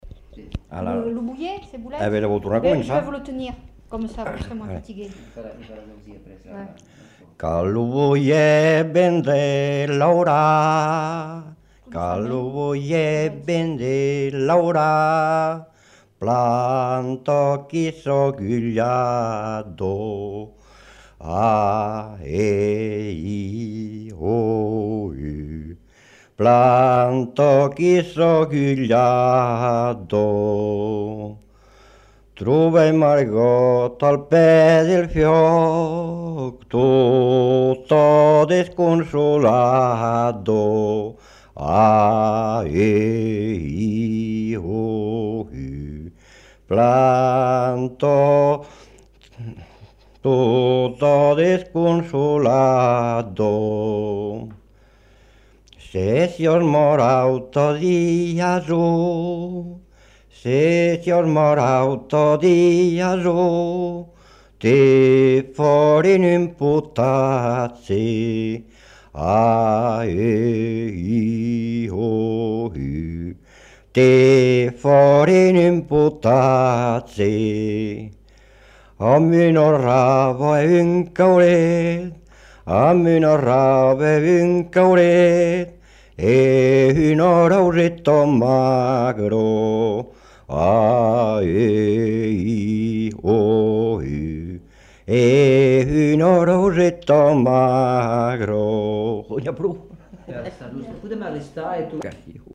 Aire culturelle : Rouergue
Lieu : Réquista
Genre : chant
Effectif : 1
Type de voix : voix d'homme
Production du son : chanté